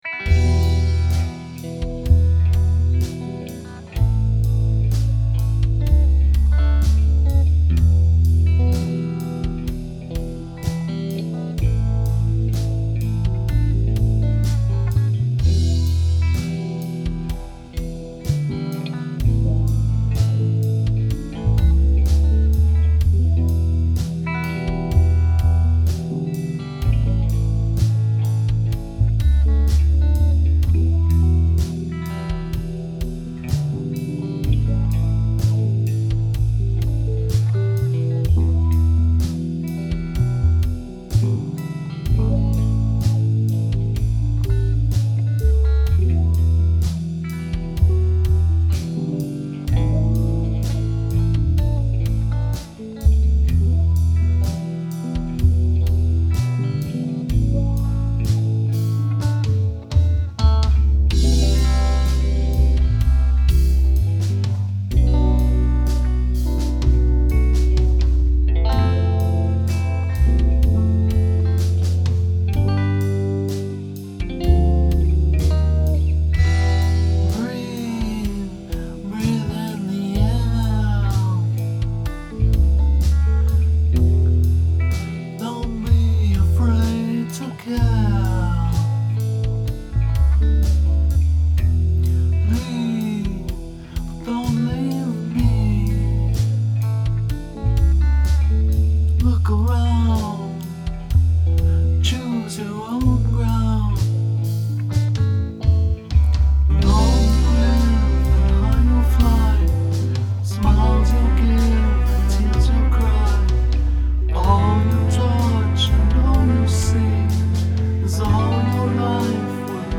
That's me on rhythm and lead.